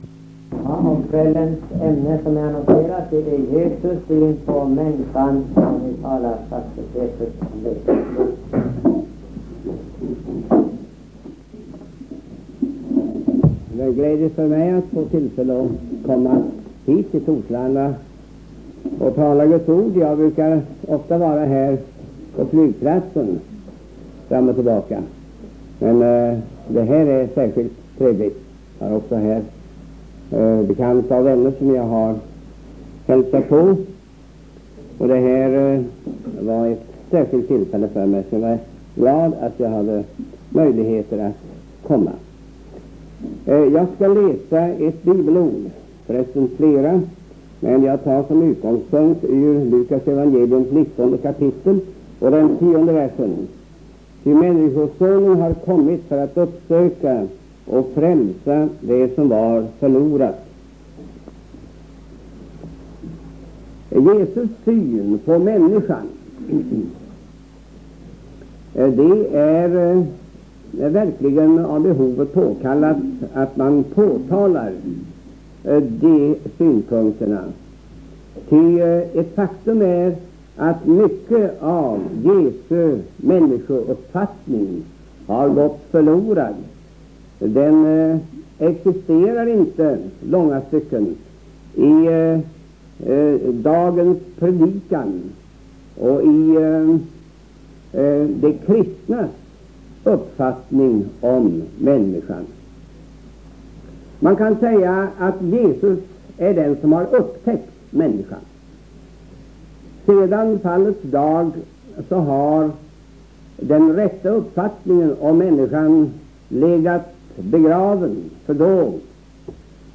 Lewi PethrusSeries: Historiska predikningar